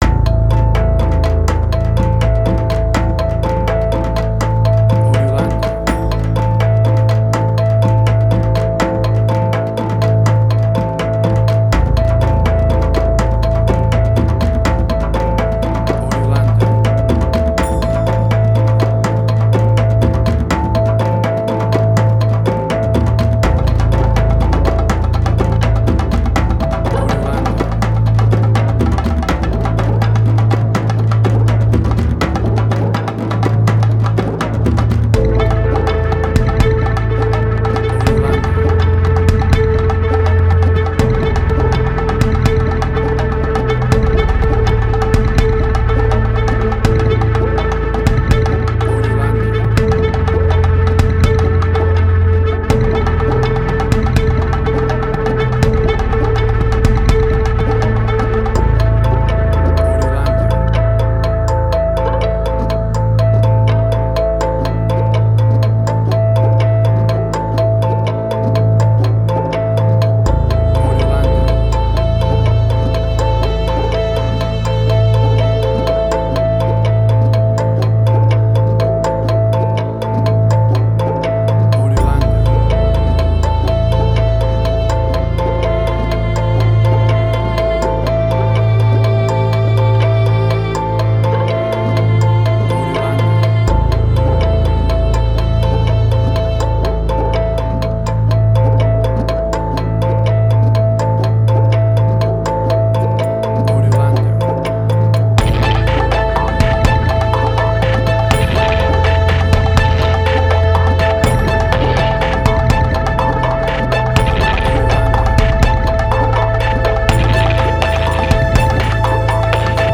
Middle Eastern Fusion.
Tempo (BPM): 123